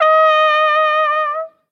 Corneta manchega